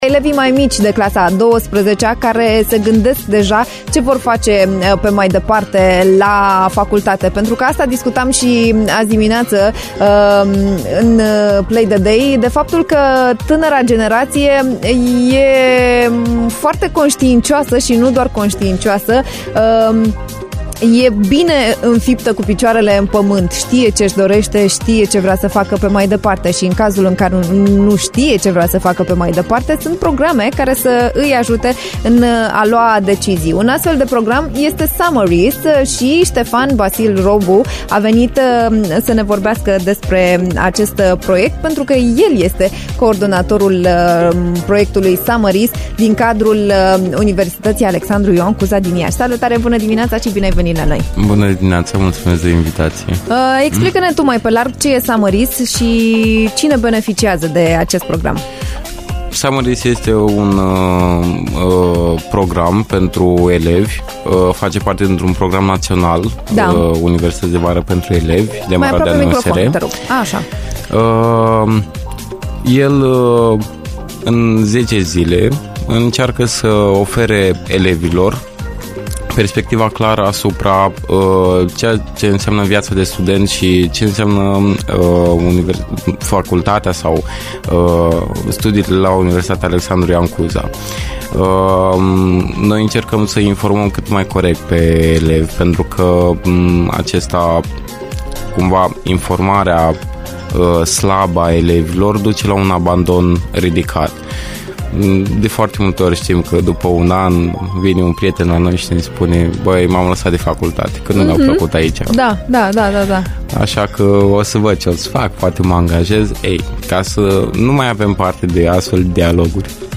In Be the HIT, am stat de vorbă